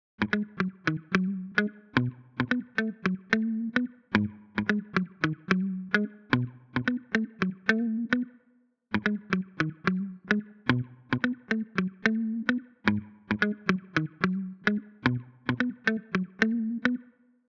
Tag: 110 bpm Funk Loops Bass Guitar Loops 3.20 MB wav Key : Unknown